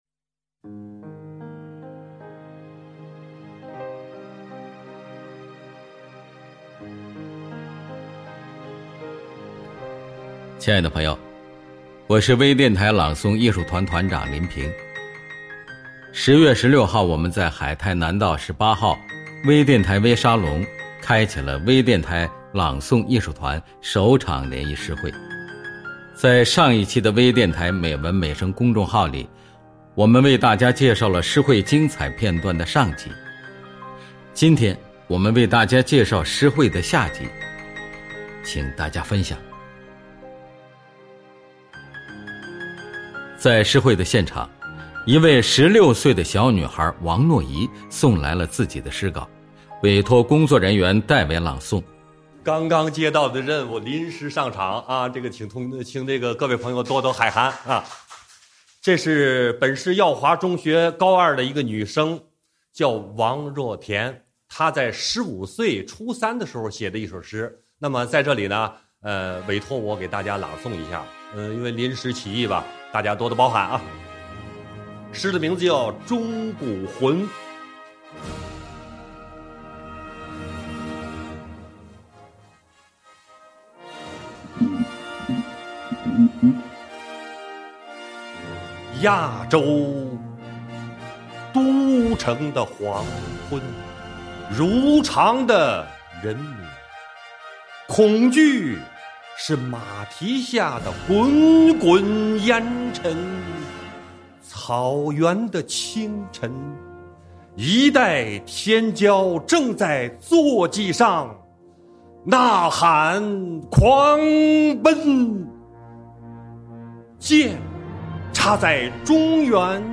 薇电台朗诵艺术团成立暨首场联谊诗会（下） - 北美网备份站 - Powered by Discuz!